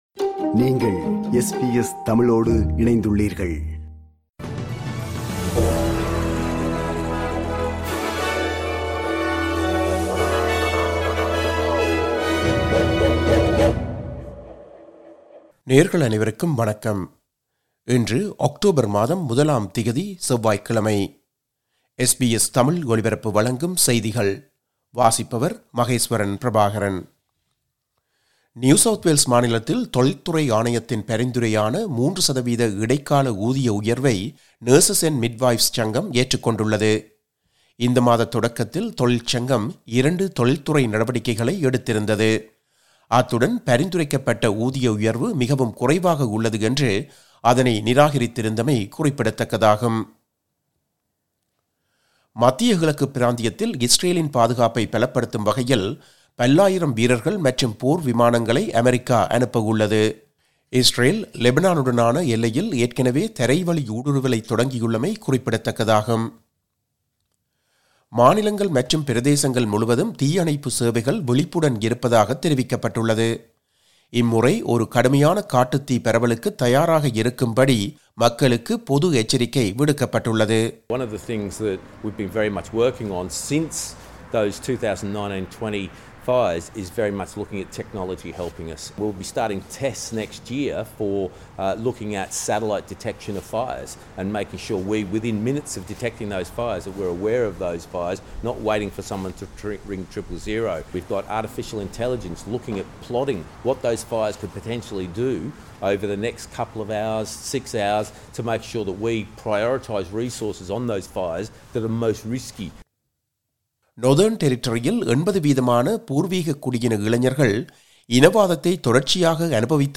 SBS தமிழ் ஒலிபரப்பின் இன்றைய (செவ்வாய்க்கிழமை 01/10/2024) செய்திகள்.